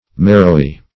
\Mar"row*y\